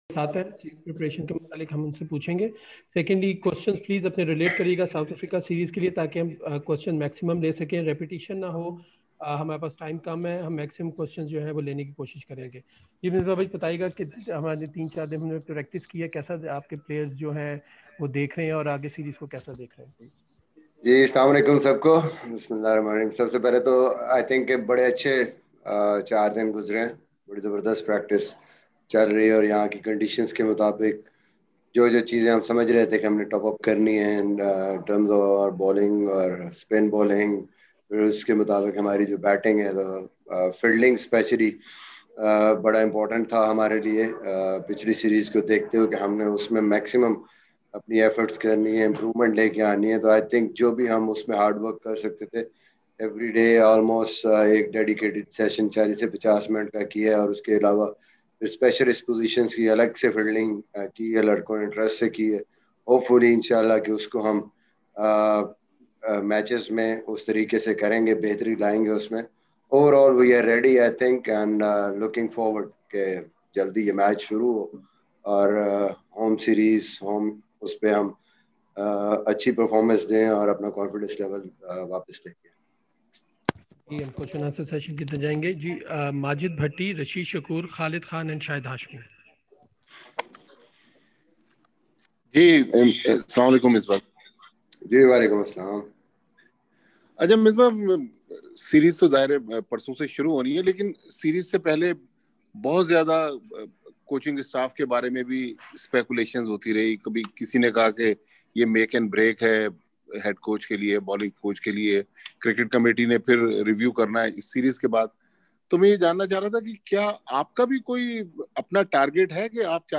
Misbah-ul-Haq, the head coach of Pakistan men’s national cricket team, interacted with media via videoconference call today, ahead of the first Test match against South Africa starting from 26 January at the National Stadium Karachi.